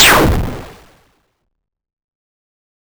lasershot.wav